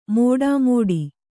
♪ mōḍāmoḍi